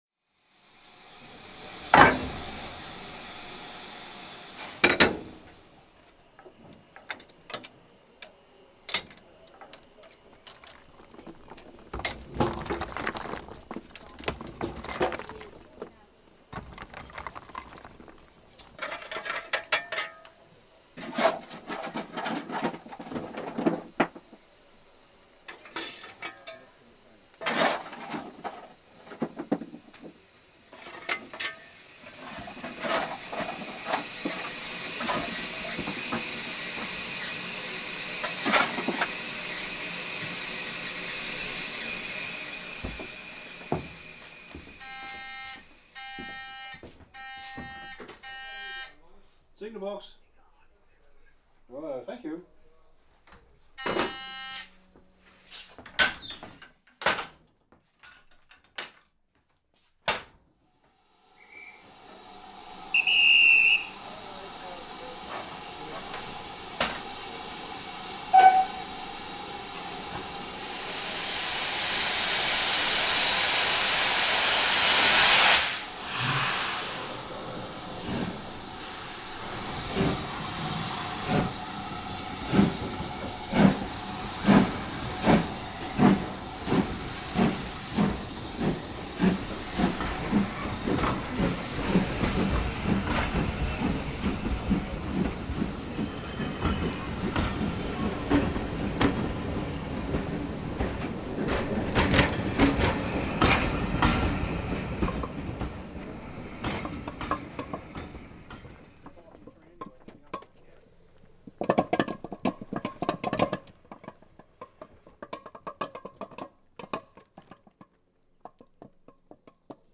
Eight soundscapes from the south of England
Recording has become a habit, a method of engaging with the world around me. I use an array of contact microphones, hydrophones and other microphone technologies and recording methods, to help me listen in on the acoustic environment.
The concentrated temporality of train stations is augmented by the steam engine, the lull of birdsong and occasional footfall closes quietly behind the cacophonous weight of arrival, as the train yelps to a standstill.